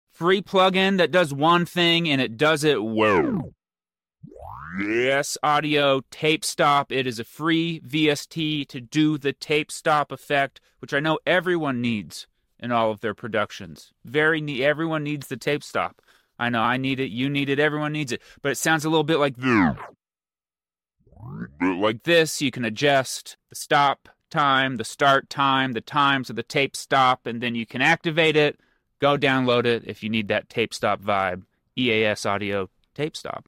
free vst Mp3 Sound Effect EAS AUDIO TAPESTOP: free vst for an easy tape stop sound, I do dig this plugin and feel like it’s more user friendly than other ones, when I’m looking to use an effect like this I’m also looking to make it happen as quickly as possible and this does that!!